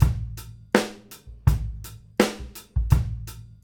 GROOVE 120BL.wav